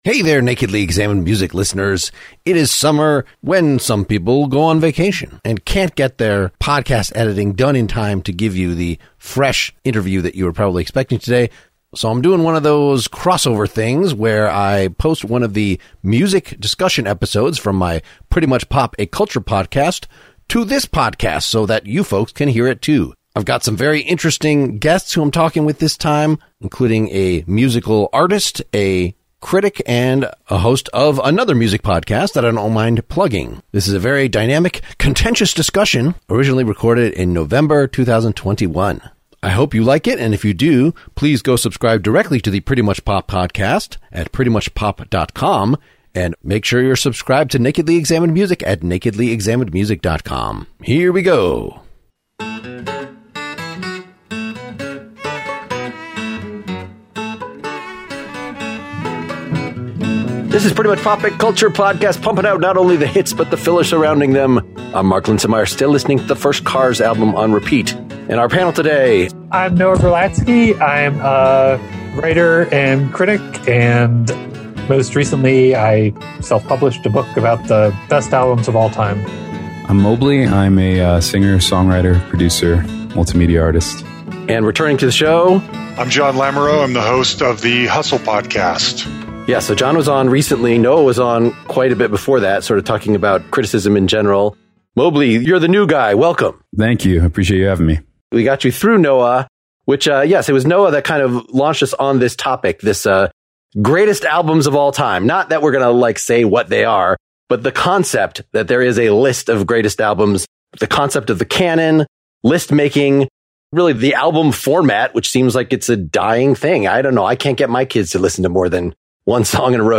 It's a music discussion episode! How does canonization work in popular music?